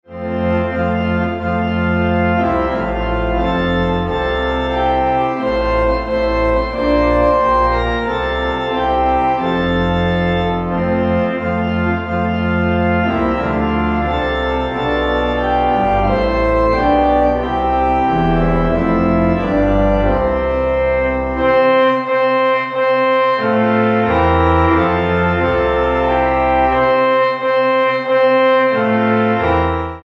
Organ